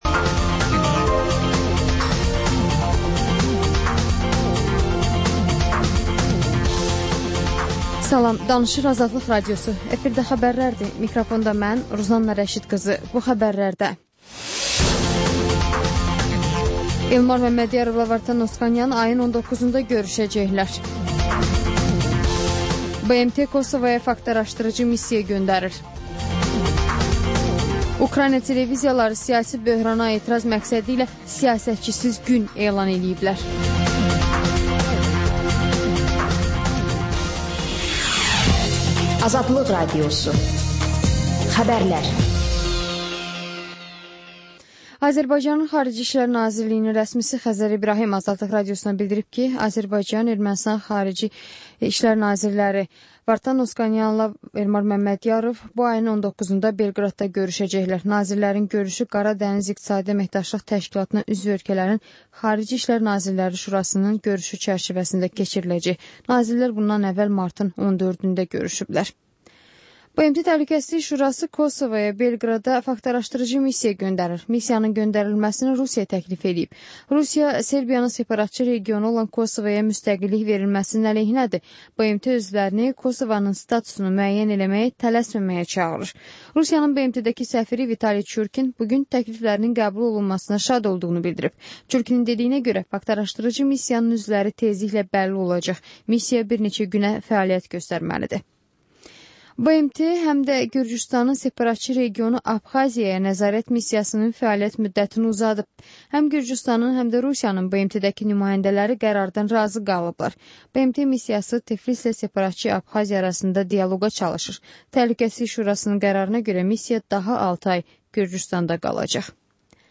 Xəbərlər, müsahibələr, hadisələrin müzakirəsi, təhlillər, sonda XÜSUSİ REPORTAJ rubrikası: Ölkənin ictimai-siyasi həyatına dair müxbir araşdırmaları